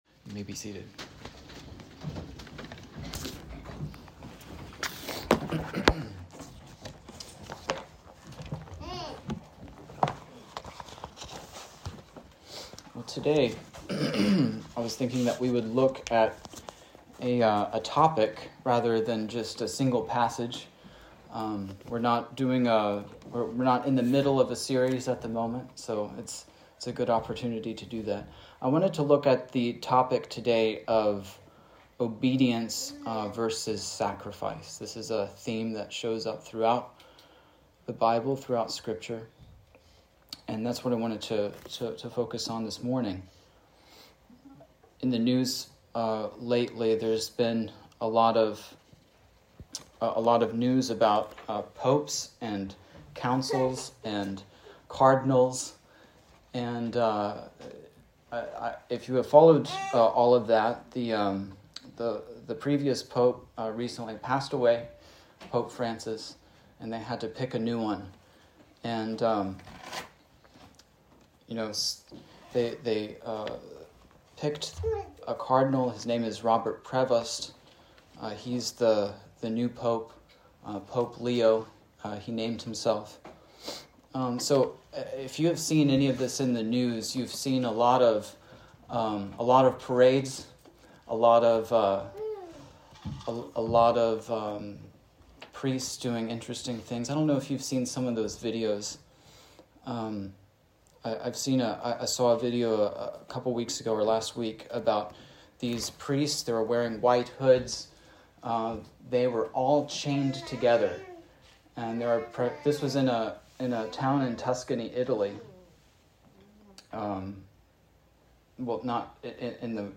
This sermon explores the biblical theme that God delights more in obedience than in outward sacrifice. It examines the story of King Saul's disobedience and delves into the Hebrew words 'Shema' (to hear and obey) and 'Hesed' (steadfast love and loyalty), applying these principles to both unbelievers and believers.